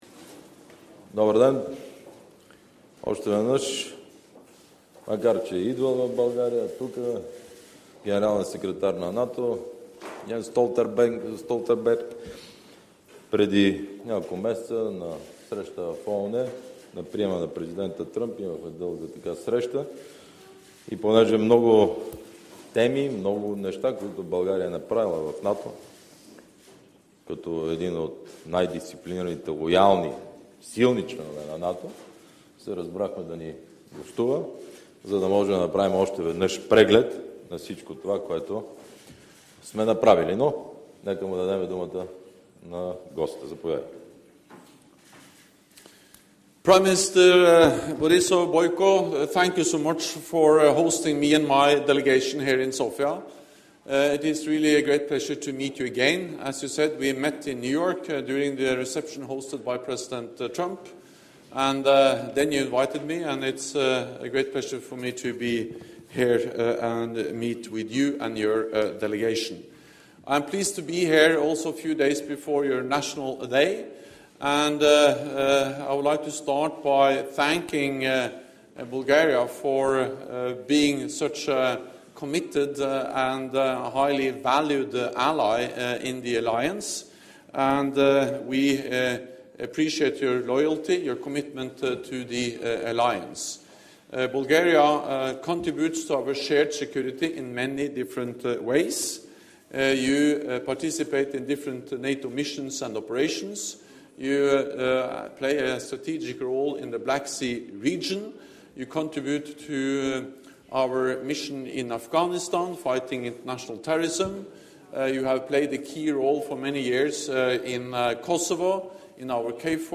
Press statement by NATO Secretary General Jens Stoltenberg with the Prime Minister of Bulgaria, Boyko Borissov
(As delivered)